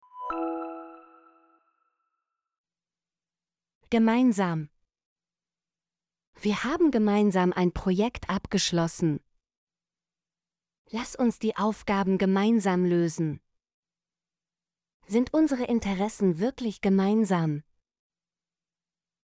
gemeinsam - Example Sentences & Pronunciation, German Frequency List